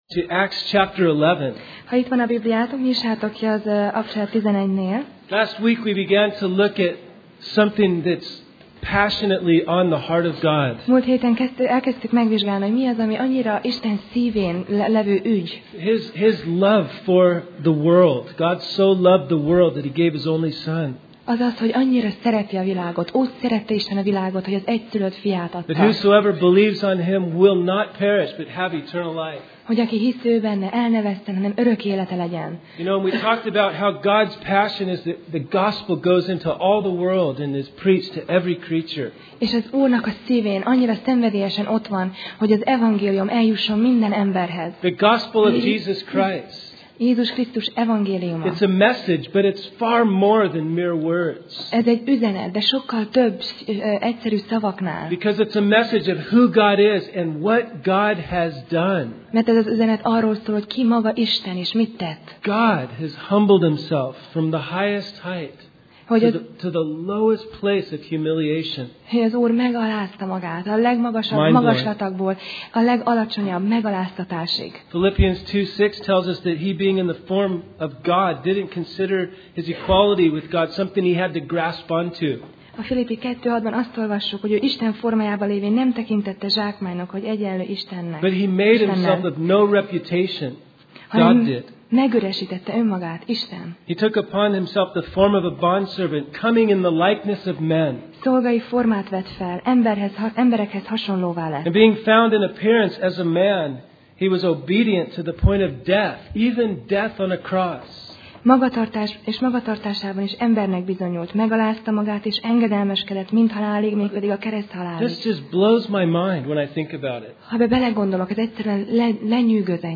Tematikus tanítás Alkalom: Vasárnap Reggel